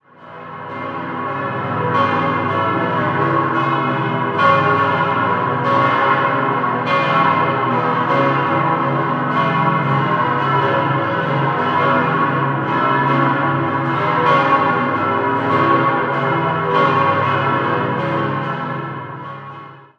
Idealsextett: gis°-h°-cis'-e'-fis'-gis'
Das Geläute in Wattwil ist unter Glockenfreunden wegen seiner gigantischen Lautstärke, die ihresgleichen sucht, bekannt.